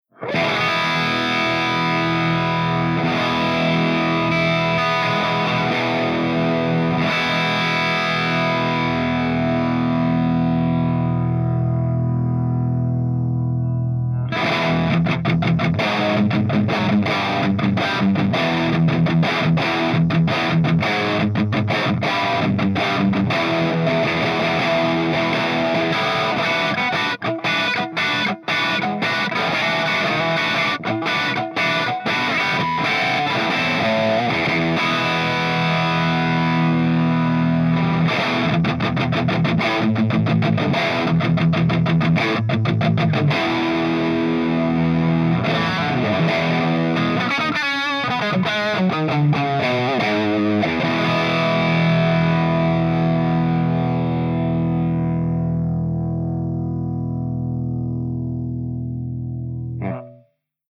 127_MARSHALLJCM800_CH2HIGHGAIN_GB_HB